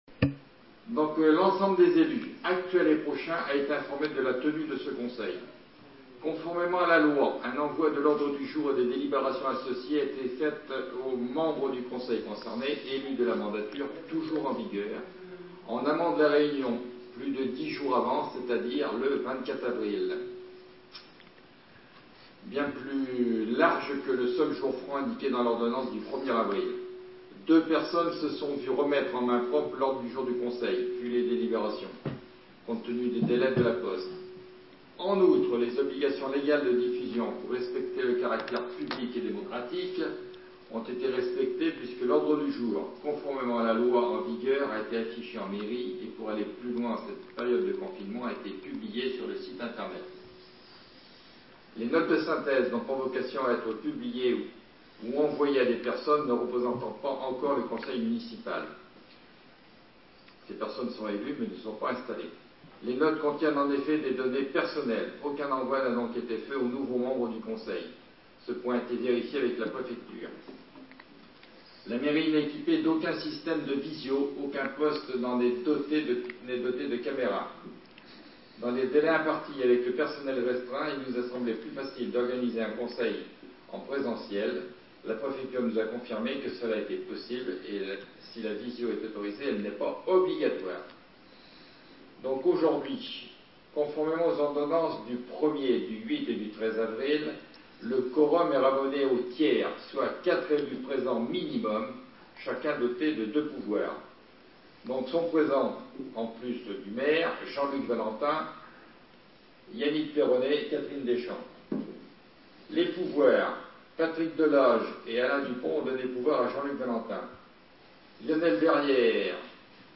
Enregistrement audio du Conseil Municipal du 05 mai 2020